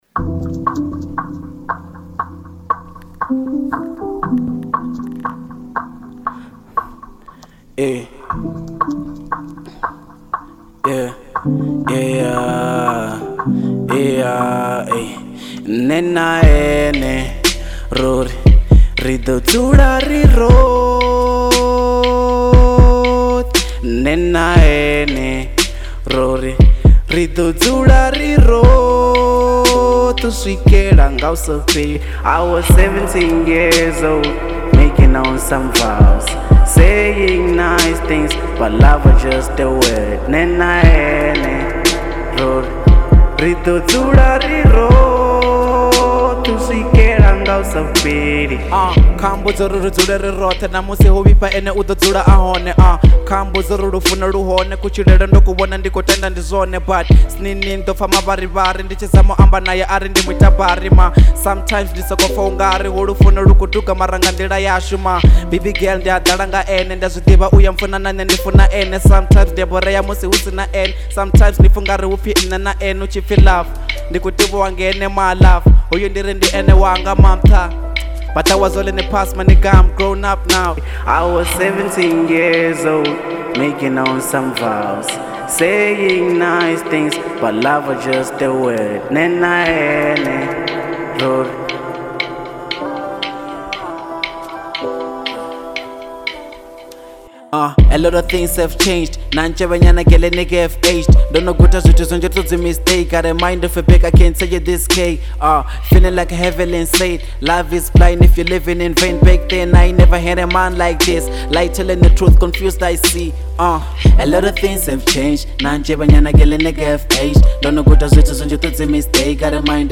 03:15 Genre : Venrap Size